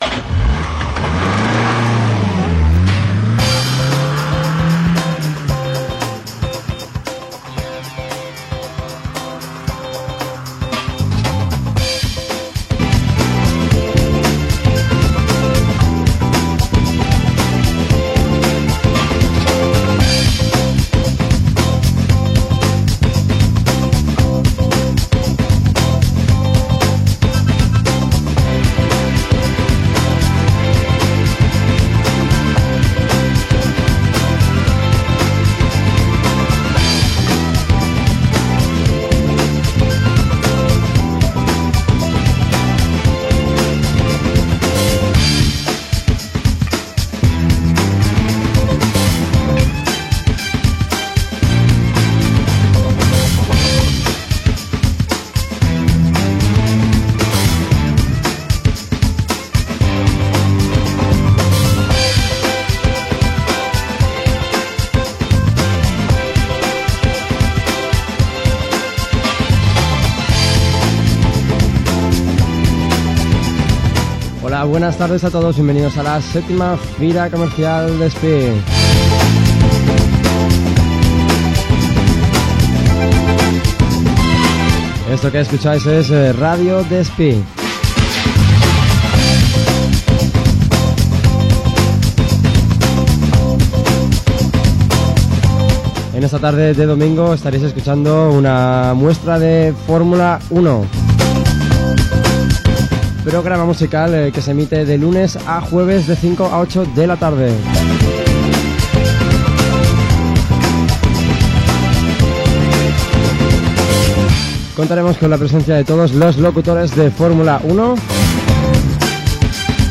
Edició especial del programa musical Fórmula 1 des de la 7ena edició de la Fira Despí.
Musical